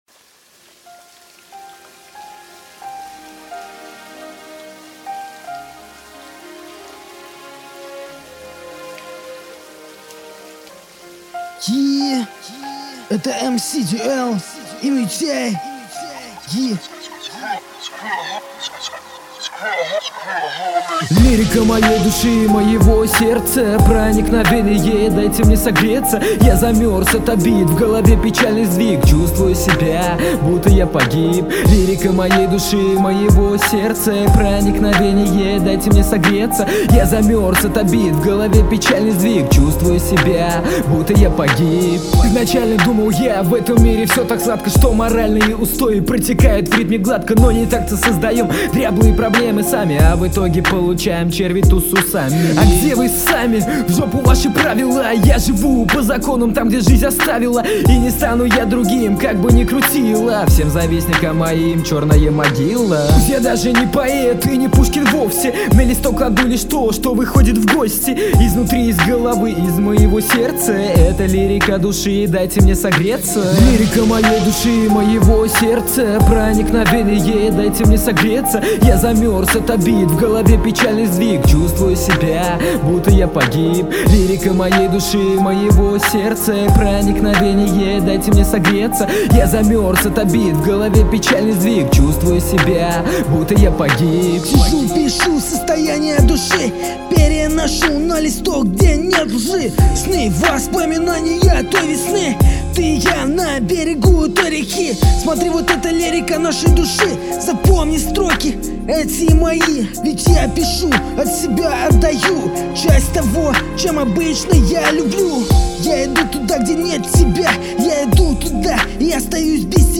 RAP 2010